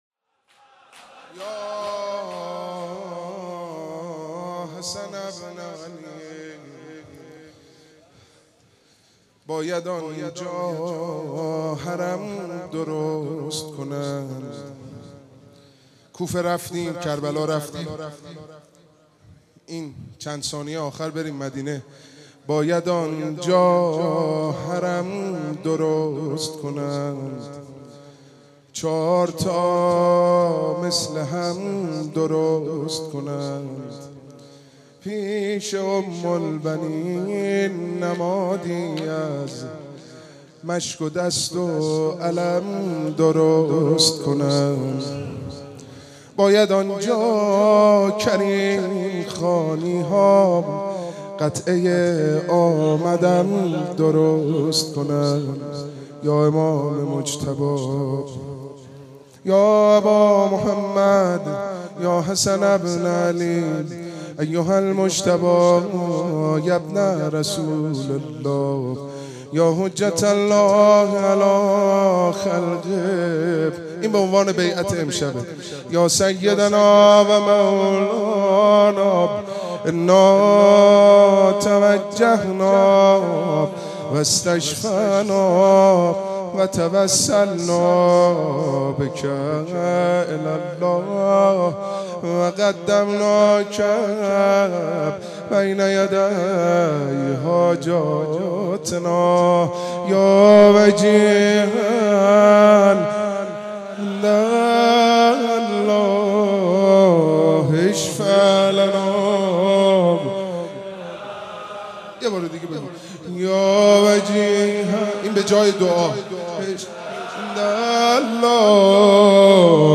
شب بیست و دوم ماه مبارک رمضان؛ هیئت رایه العباس(ع)؛ تیر93
شور